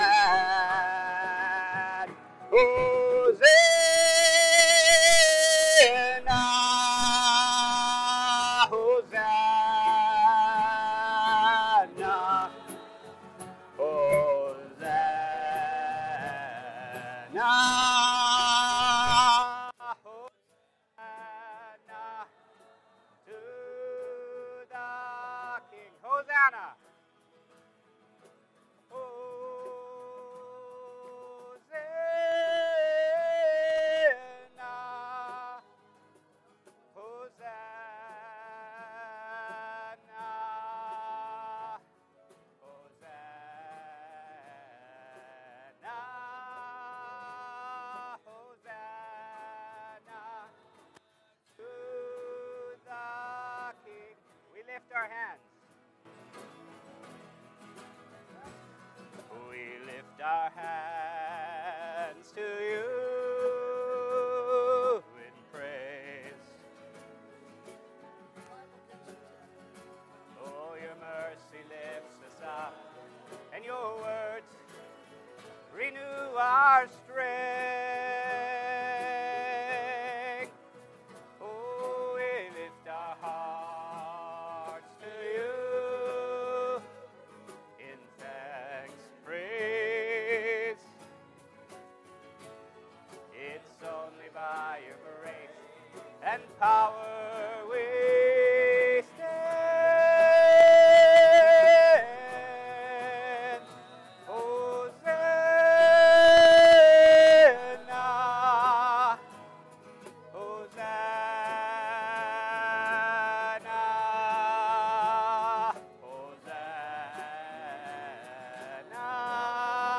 Unedited Praise 1 from Convention 2020
Unedited-Praise-2020-SCC-Convention-1.mp3